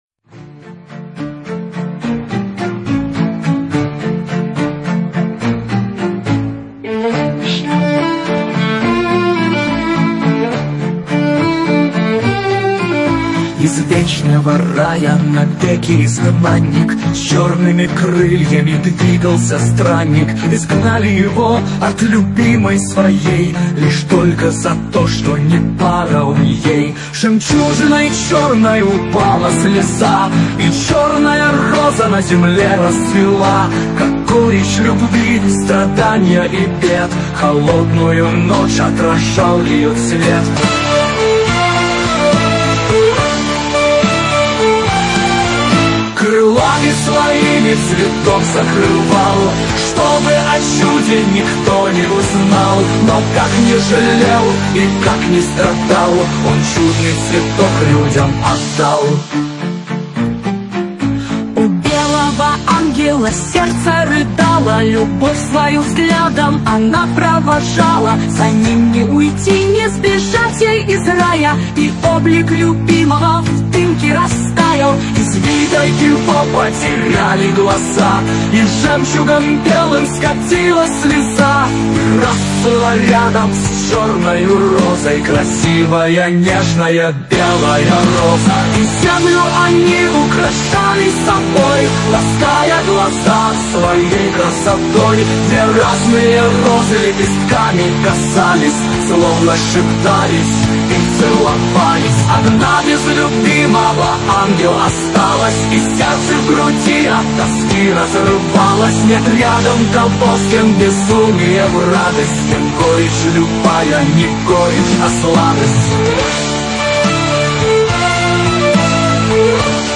Avtorskaja_pesnja_Angelov_sljozy__Ballada_o_ljubvi.mp3